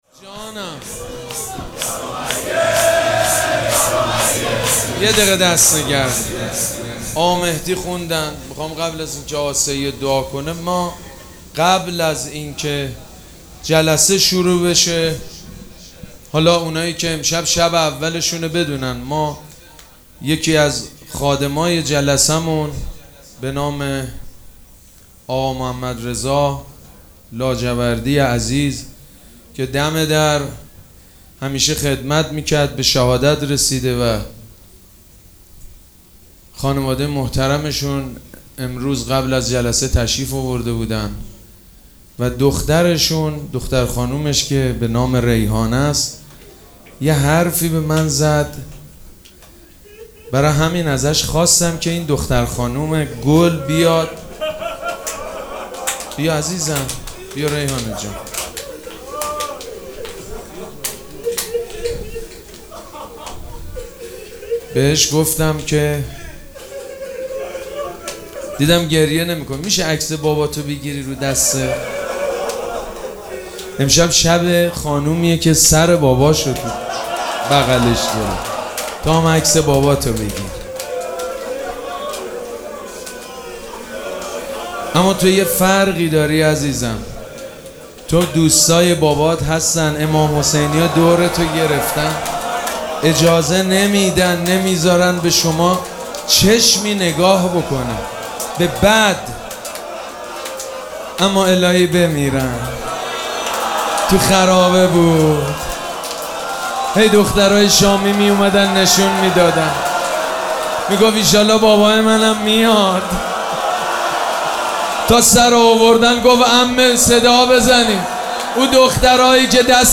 مراسم عزاداری شب سوم محرم الحرام ۱۴۴۷ حاج سید مجید بنی فاطمه